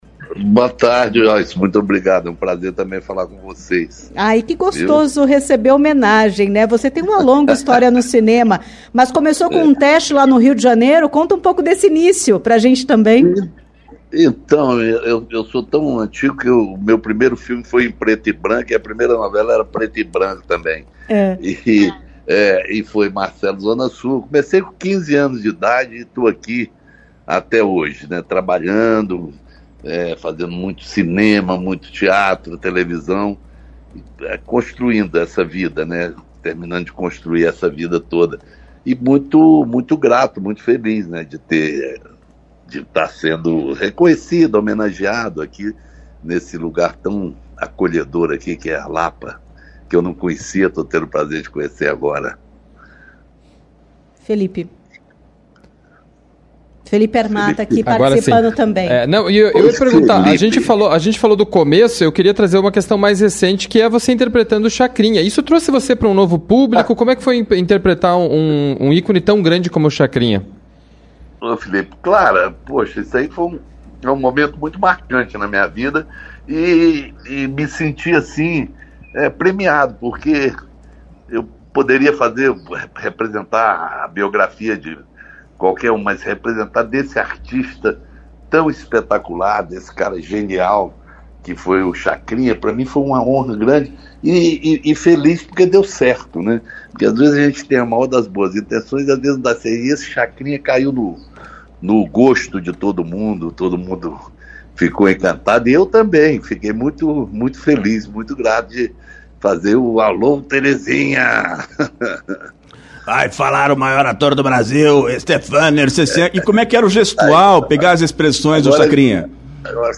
Para falar sobre o festival e sobre essa premiação, a CBN Curitiba conversou com o ator Stepan Nercessian.
CBN-Entrevista-8-11.mp3